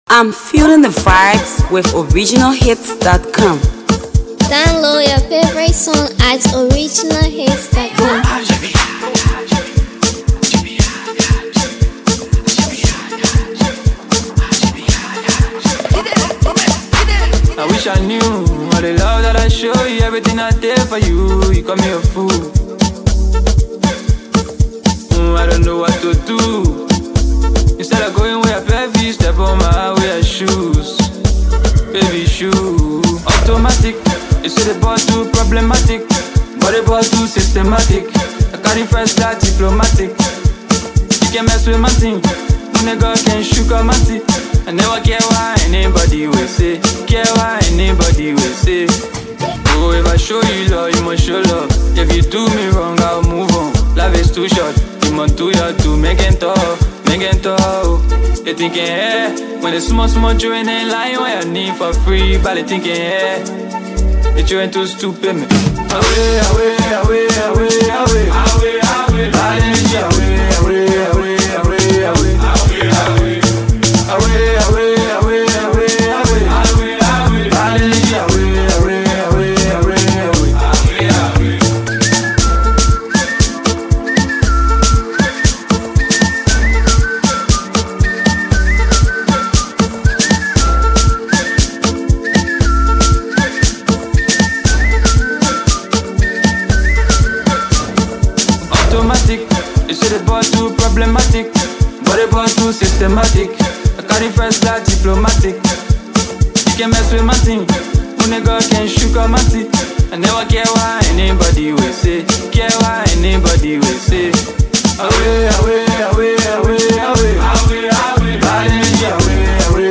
amapiano
Afro Pop